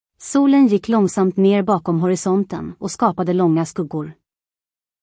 2026-04-08_233102_mp3_Swedish_sv-SE-SofieNeural.mp3 transkriberad processed 0.03 MB 2026-04-08 23:31:02